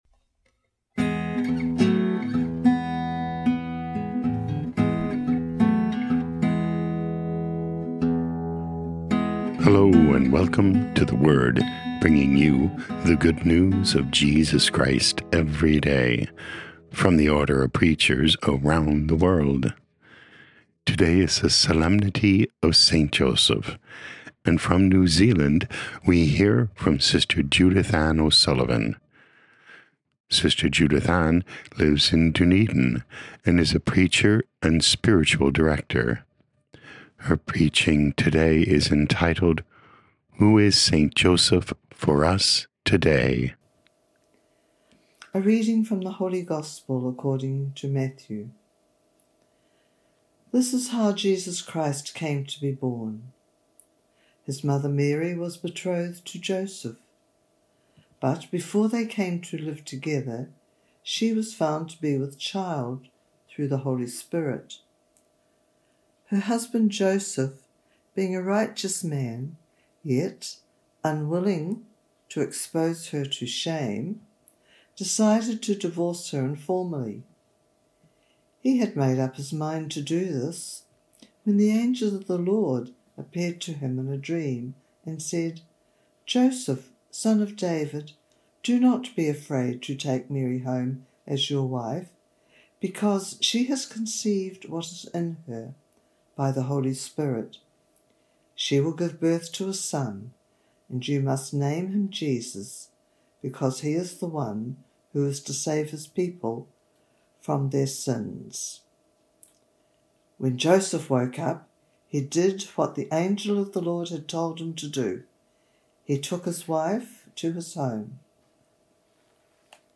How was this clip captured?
Podcast: Play in new window | Download For 19 March 2025, Solemnity of St. Joseph, based on Matthew 1:18-24, sent in from Dunedin, New Zealand.